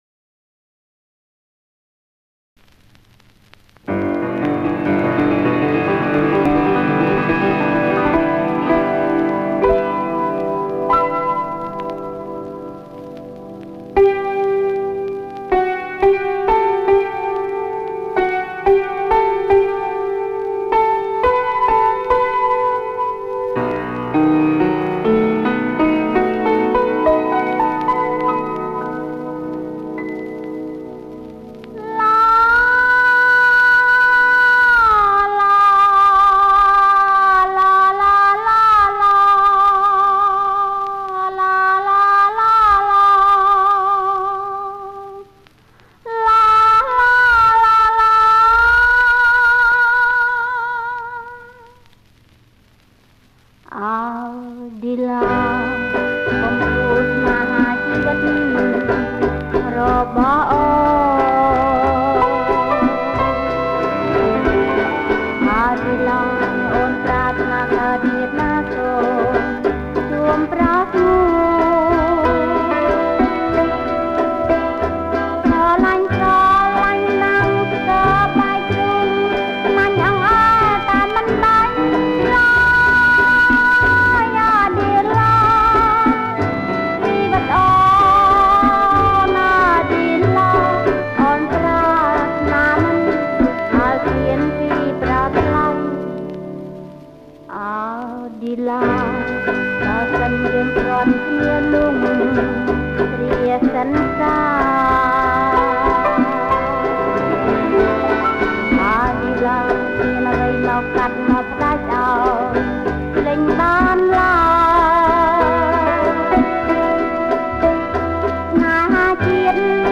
• ប្រគំជាចង្វាក់ Rumba Lent